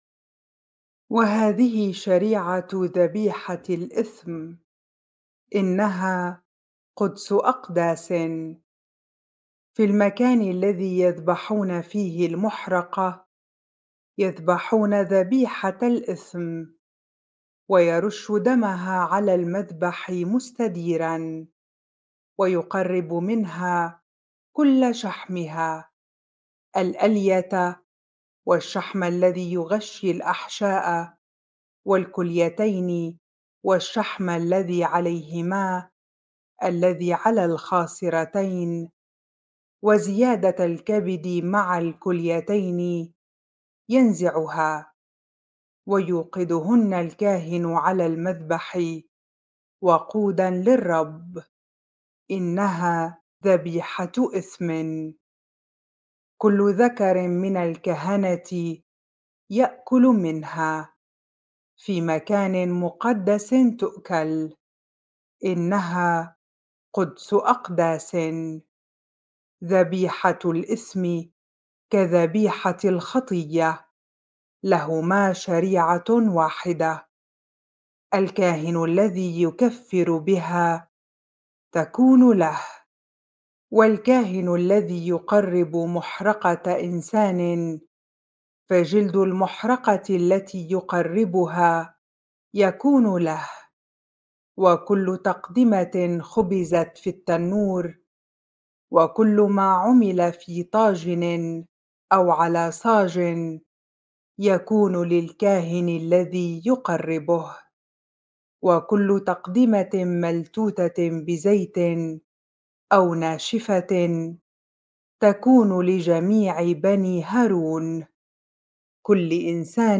bible-reading-leviticus 7 ar